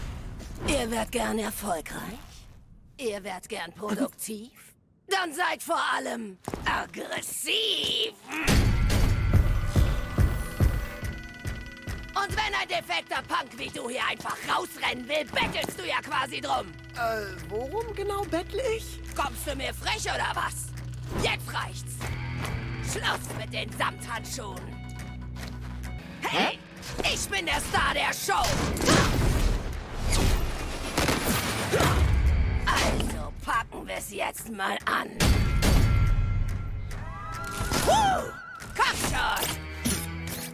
Hi Fi Rush (Rekka) – rabiat/tough